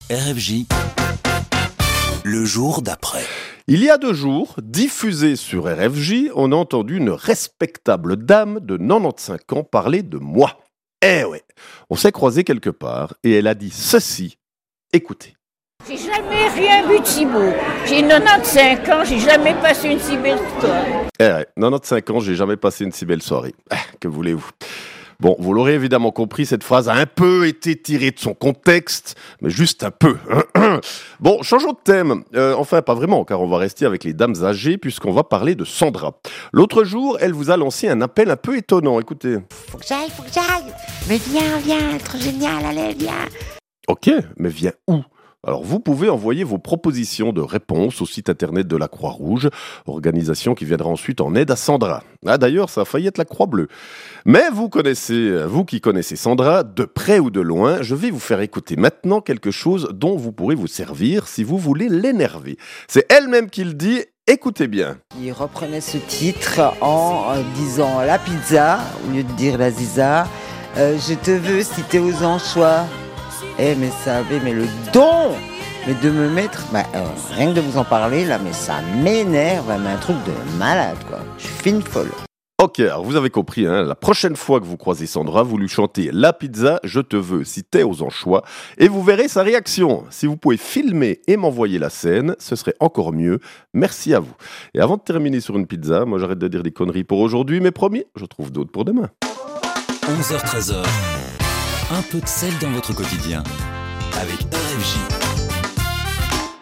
L'actu de la veille, traitée de manière un peu décalée, sons à l'appui, c'est ça "Le jour d'après !"